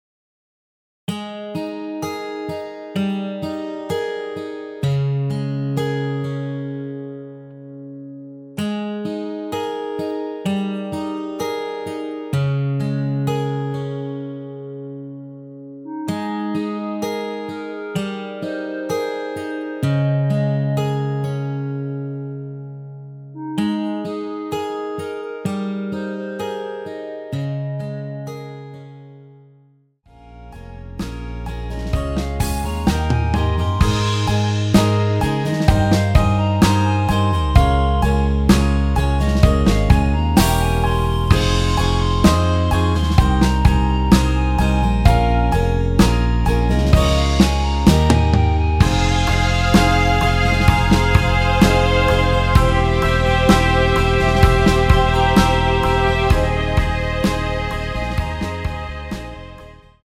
원키에서(+6)올린 MR입니다.
Ab
앞부분30초, 뒷부분30초씩 편집해서 올려 드리고 있습니다.